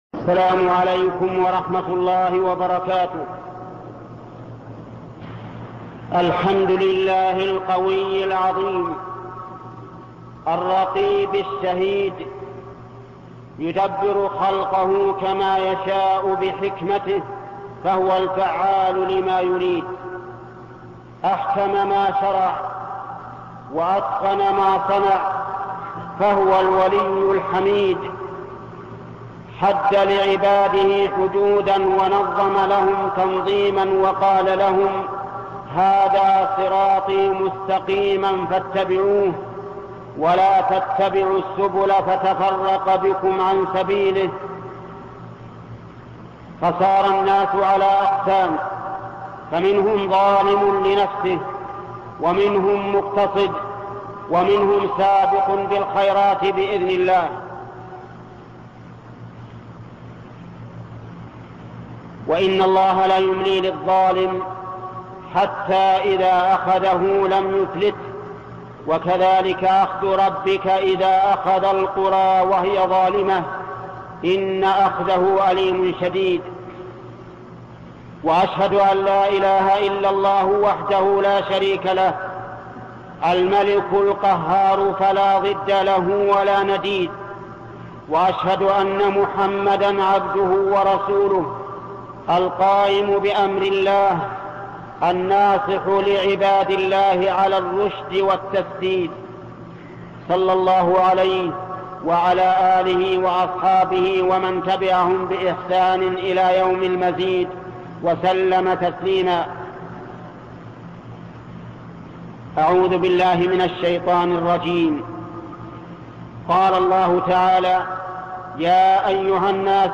خطب الجمعة - الشيخ محمد بن صالح العثيمين